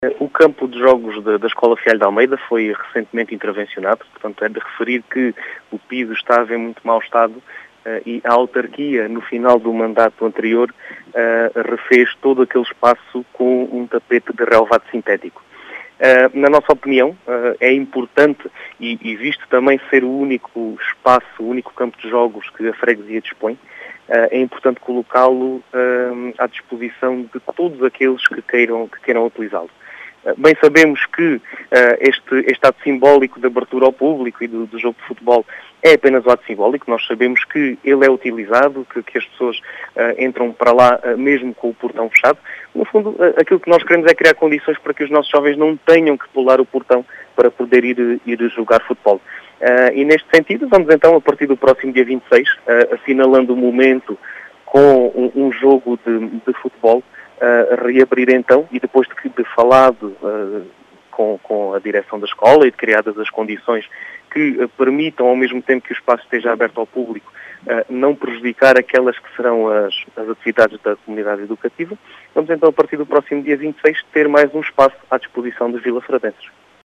A reabertura vai ser assinalada com um jogo de futebol, segundo explicou à Rádio Vidigueira, Diogo Conquero, presidente da junta de freguesia de Vila de Frades, que realçou a importância daquele espaço, que foi intervencionado.